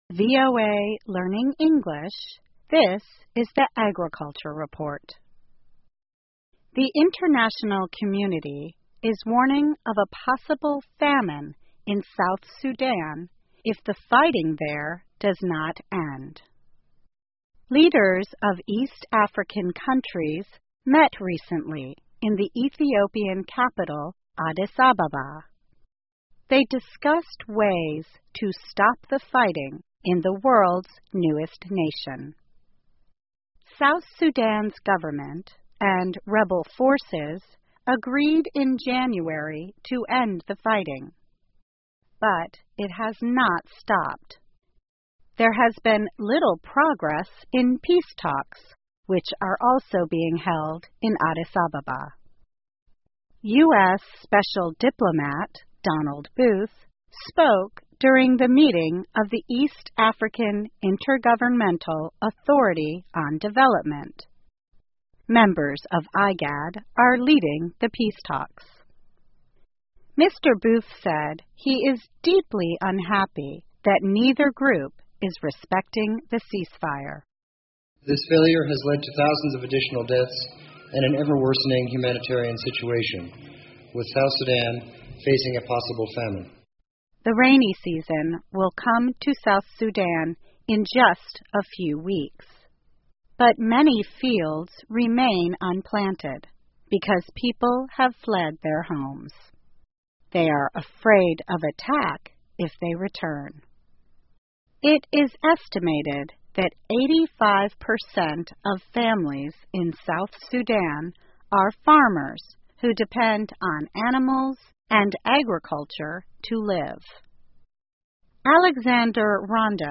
VOA慢速英语2014 International Community Warns of a Possible Famine in S. Sudan 国际社会警告南苏丹可能发生饥荒 听力文件下载—在线英语听力室